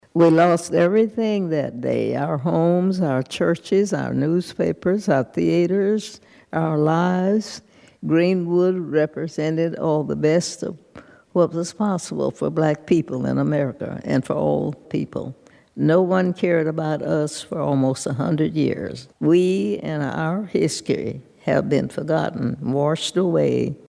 In that congressional interview, which was recorded on C-SPAN, here is what